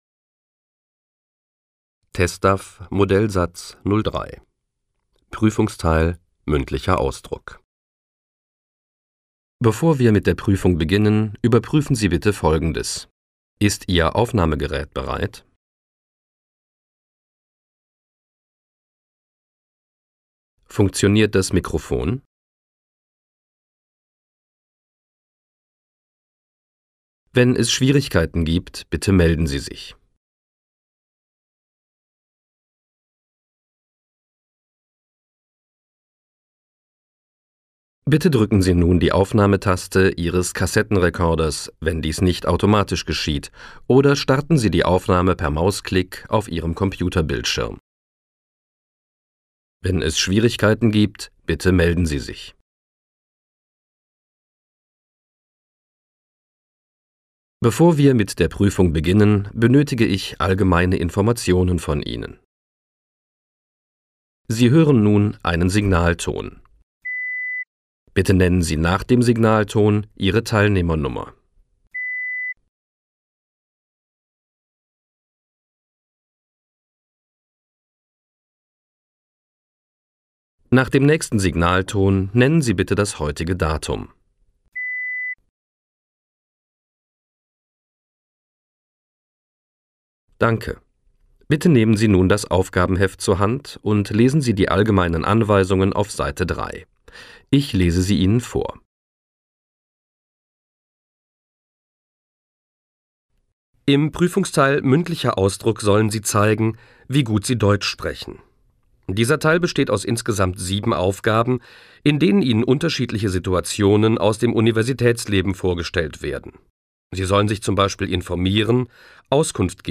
Komplette Hördatei zum MA mit Vorbereitungs– und Sprechzeiten